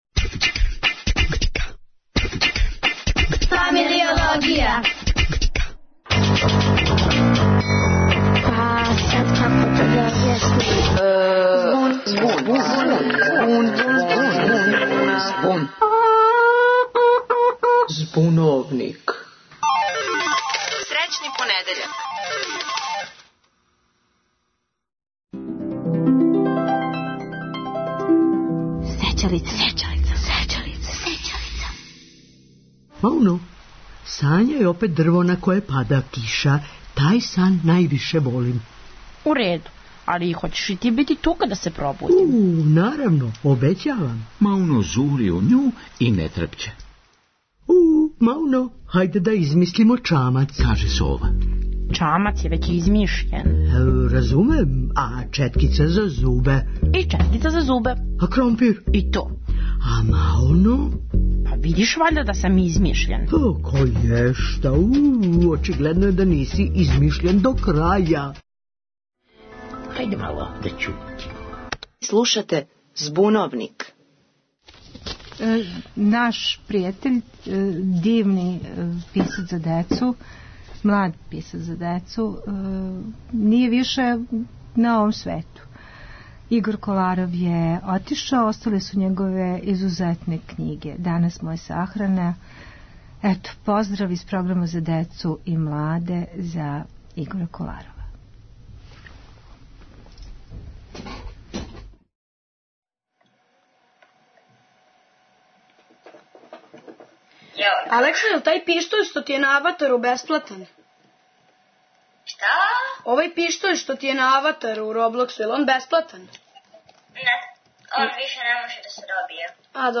О игрицама, паници на интернету, истинитим и лажним информацијама које се срећу, о "родитељској контроли" као опцији којом може да се има увид шта деца на интернету раде - говоре, као и увек у Збуновнику, деца, млади и одрасли.